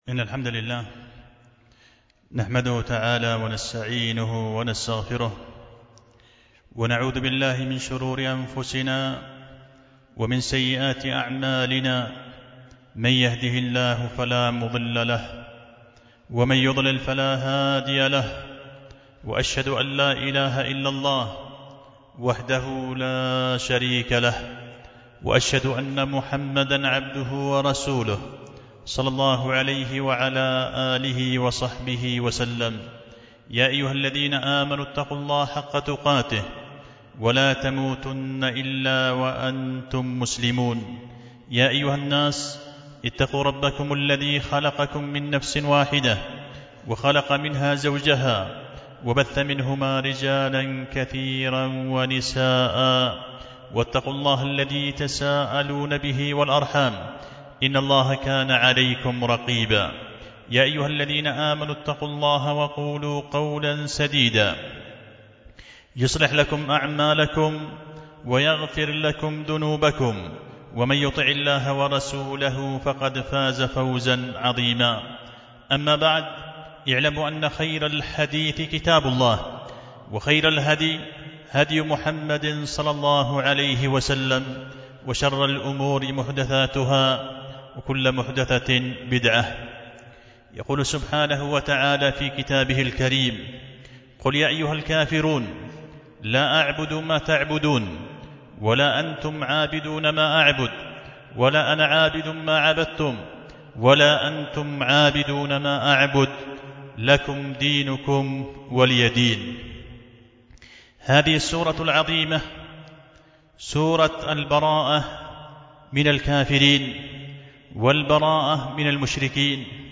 خطبة جمعة بعنوان دواء الداء في البراءة من الأعداء